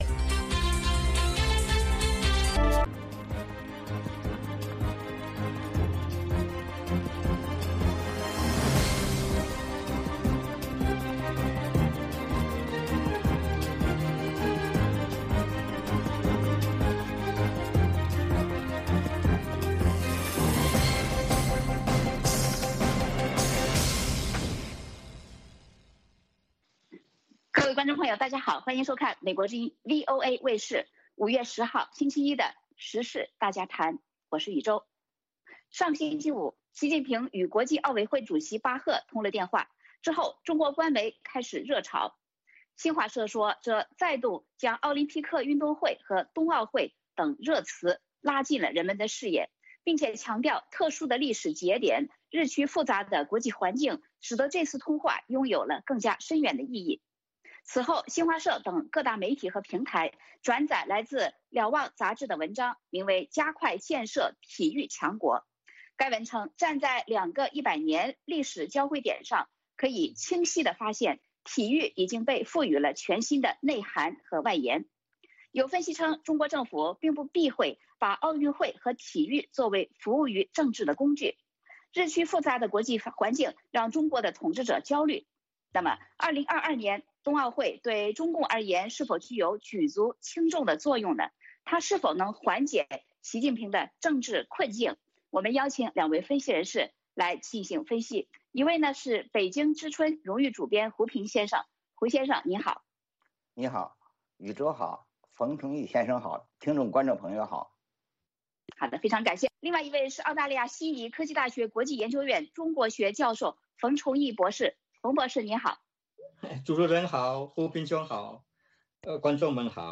时事大家谈(2021年5月10日) 习近平与巴赫通话，冬奥会能否缓解北京政治困局？嘉宾：《北京之春》杂志荣誉主编胡平